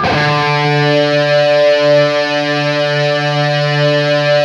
LEAD D 2 LP.wav